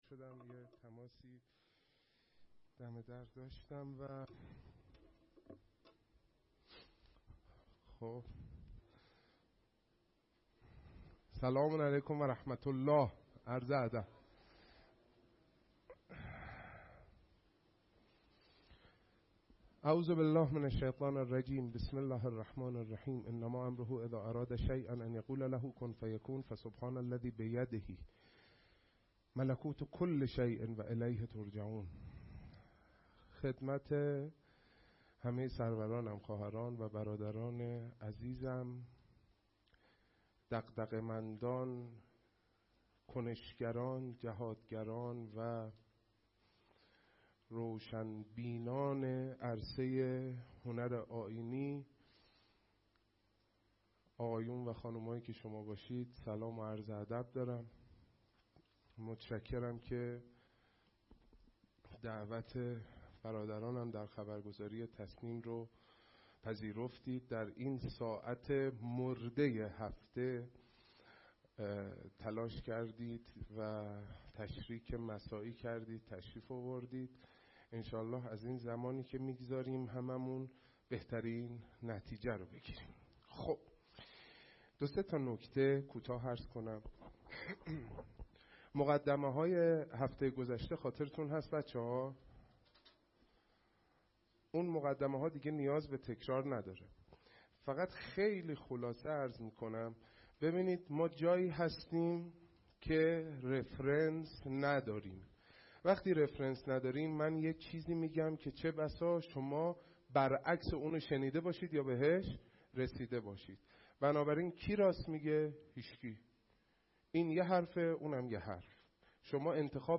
کارگاه نوحه‌سرایی|در میان شاخه‌های هنر، تصنیف آئینی هنر مظلومی است/ همه شعرها قابل تبدیل به تصنیف هستند - تسنیم
با توجه به اهمیت نوحه و شعر خبرگزاری تسنیم اولین دوره کارگاه شعر و تصنیف آیینی را در باشگاه خبرنگاران پویا برگزار کرد.